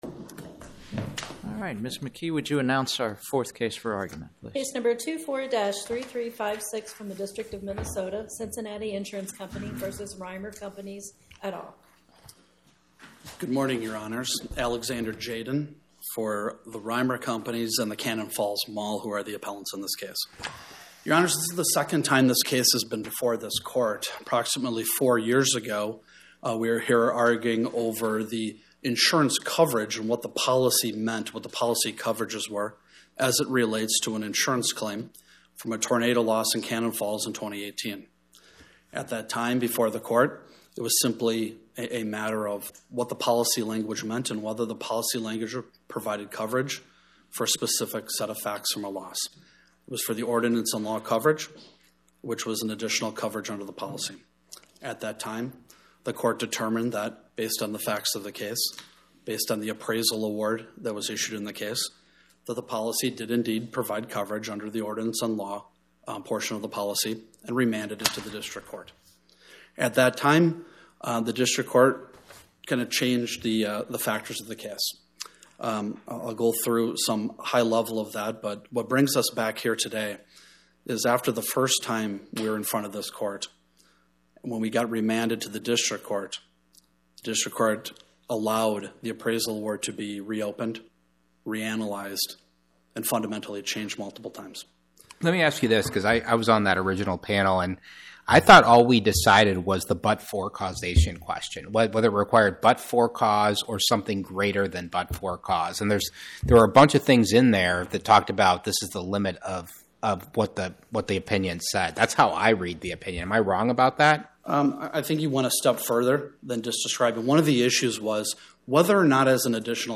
My Sentiment & Notes 24-3356: Cincinnati Insurance Company vs Rymer Companies, LLC Podcast: Oral Arguments from the Eighth Circuit U.S. Court of Appeals Published On: Tue Oct 21 2025 Description: Oral argument argued before the Eighth Circuit U.S. Court of Appeals on or about 10/21/2025